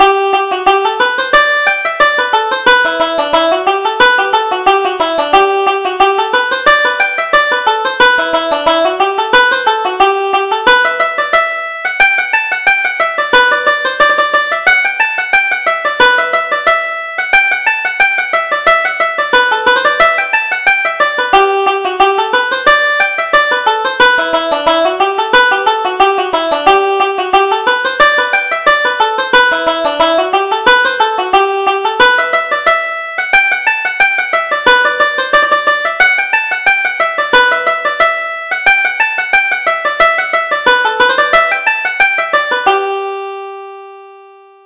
Reel: The Teetotaller's Fancy